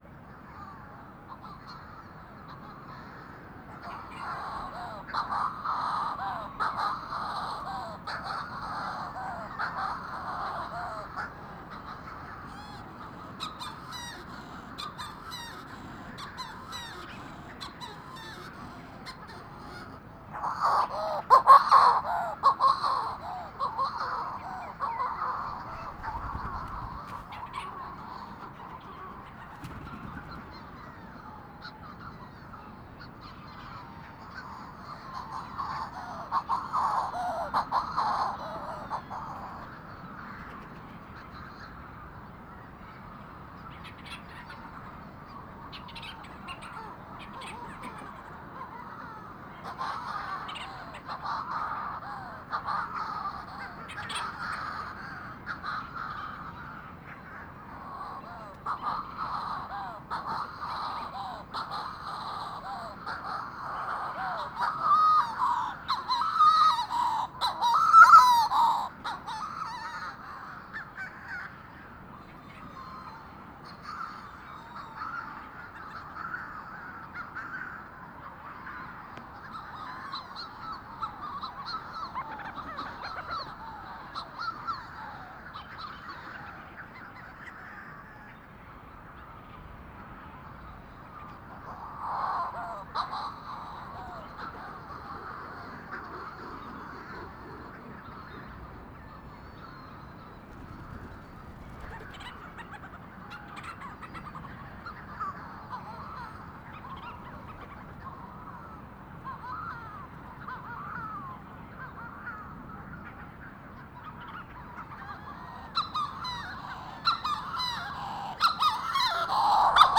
I placed my microphones on the snout of a large, bear-shaped rock, where they could pick up the ambience from all sides.
Their performance sounded all the more exotic, as Leach’s Storm Petrels were chattering and flitting about in their midst (CD1-43).
CD1-43: Manx Shearwater Puffinus puffinus Hirta, St Kilda, Western Isles, Scotland, 01:25, 24 July 2007. Calls of males and females in flight, in a colony of some 4500 pairs.
A wide range of call variation can be heard in Manx Shearwater colonies, and you can hear plenty in CD1-43. Pitch and timbre vary from deep and coarse-sounding to high and crooning. Some callers seem more hurried than others. The rate of delivery varies from bird to bird.
1-43-Manx-Shearwater.wav